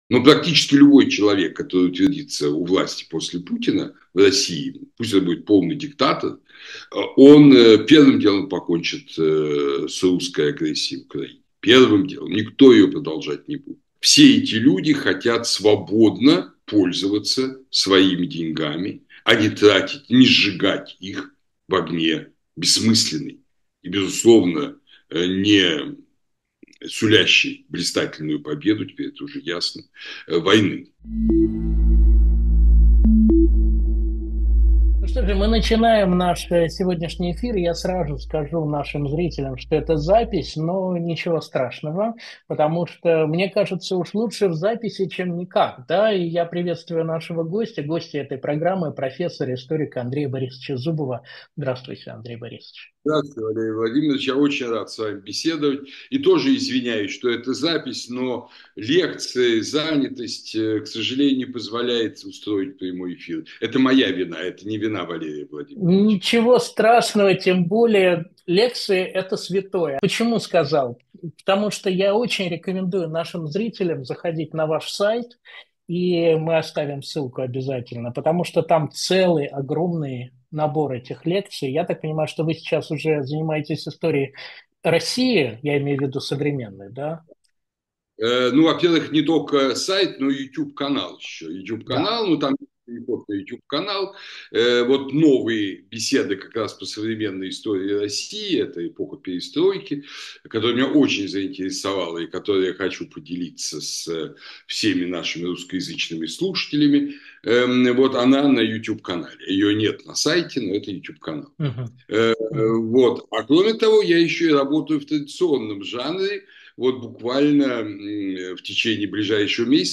Андрей Зубов историк